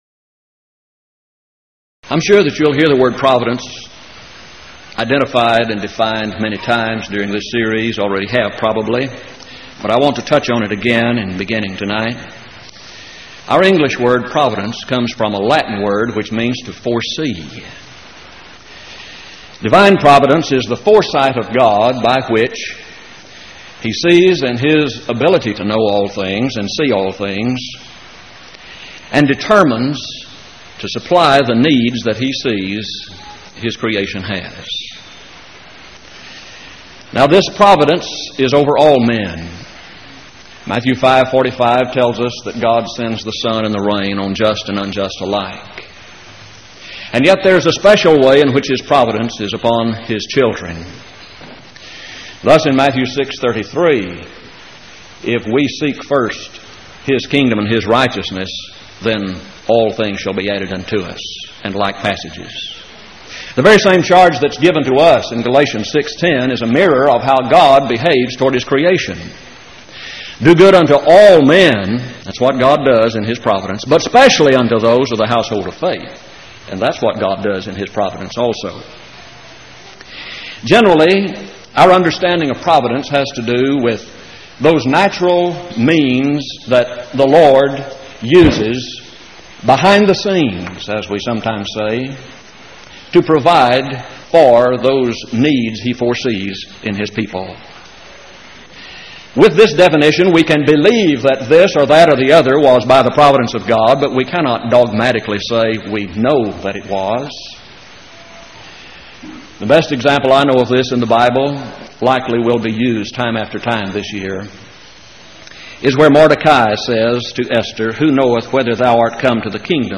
Event: 1989 Power Lectures
lecture